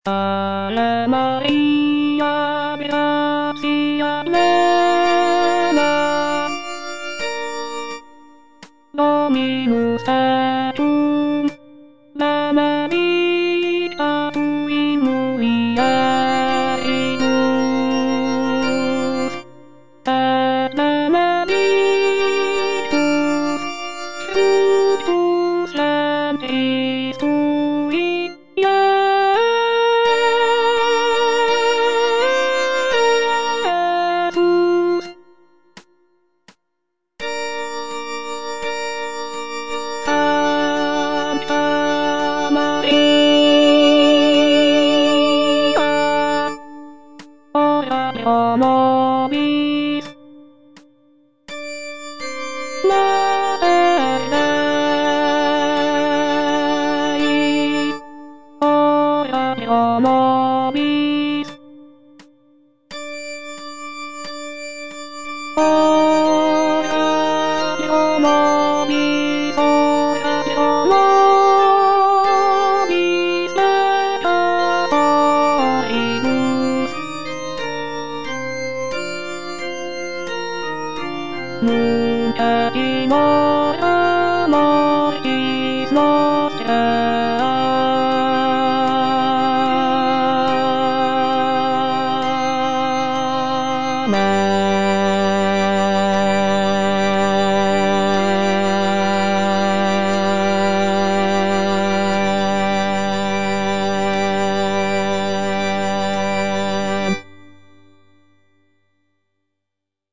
choral work
Written in a lush and lyrical style
polyphonic textures and intricate vocal lines